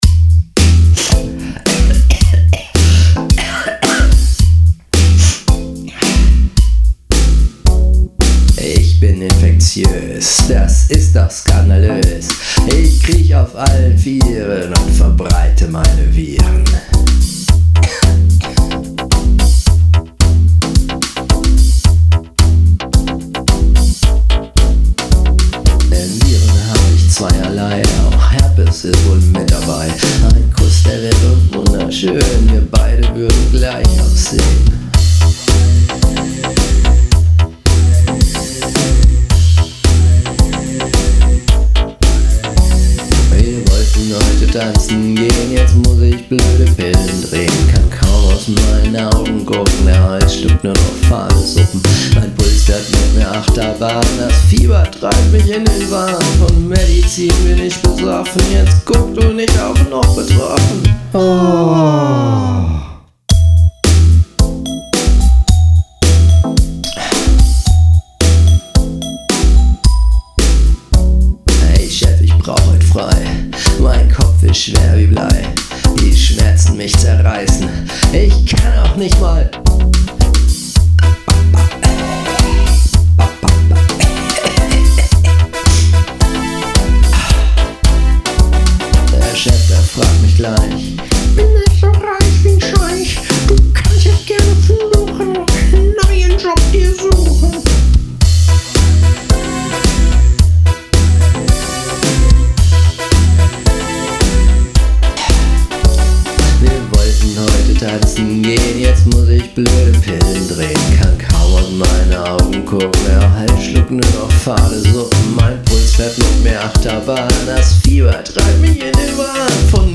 Und Du hast ihn selbst aufgenommen..oder???